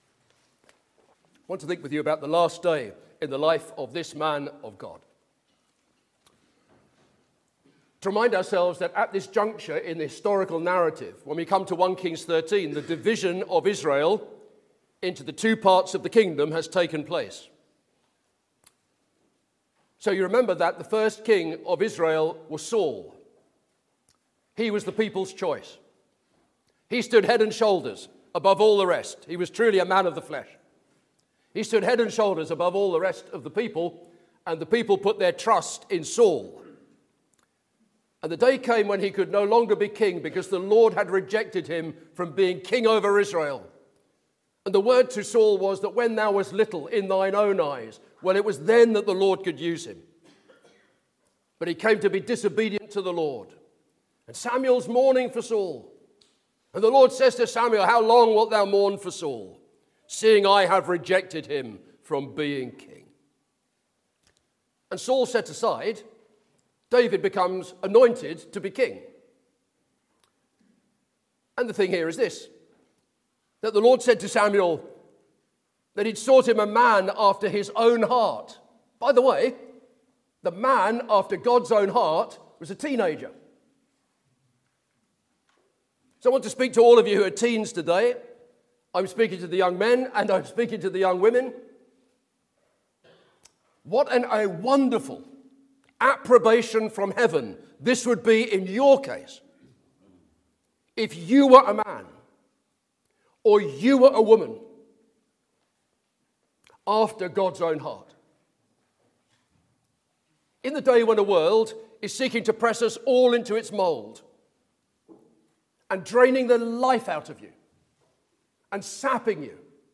2026 Easter Conference